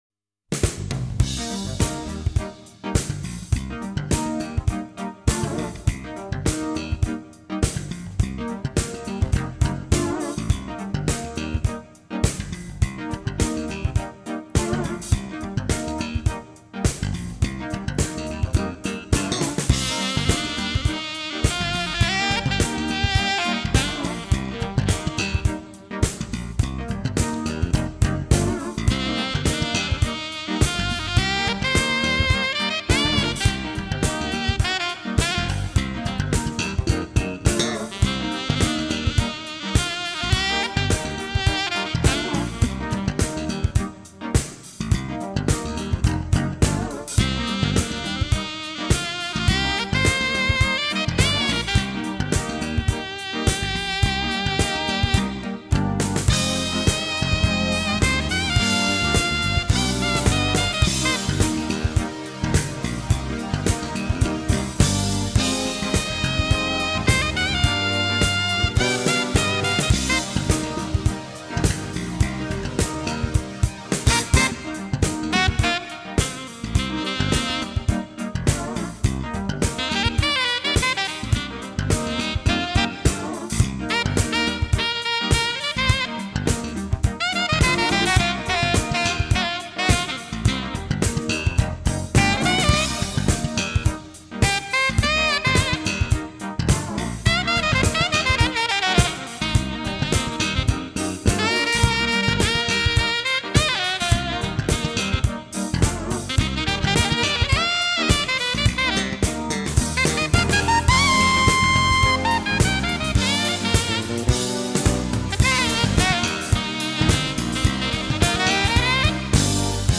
très planant
au sax